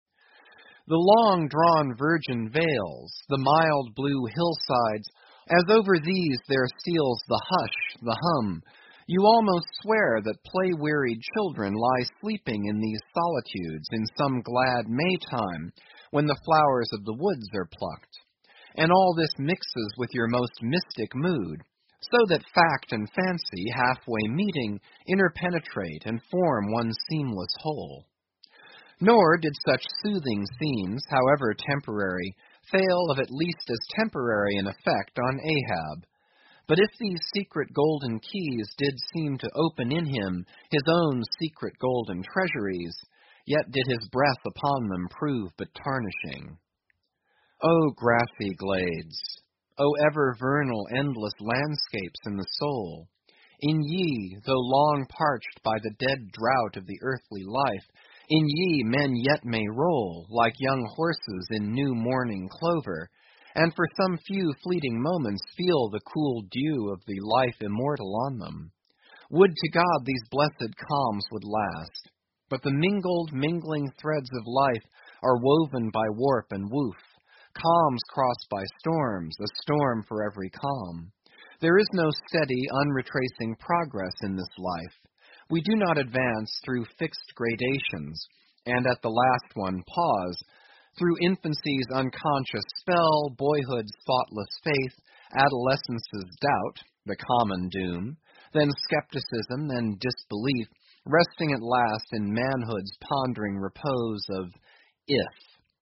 英语听书《白鲸记》第923期 听力文件下载—在线英语听力室